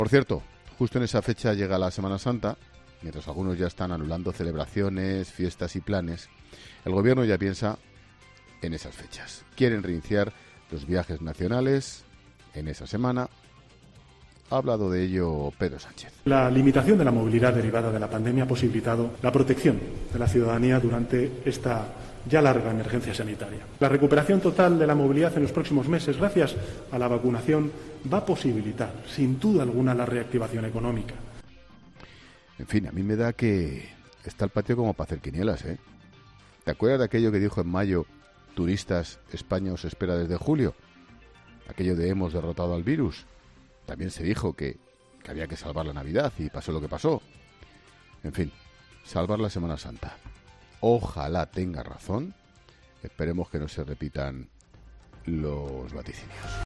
El presentador de 'La Linterna' analiza el tono esperanzador del presidente del Gobierno sobre la recuperación de la movilidad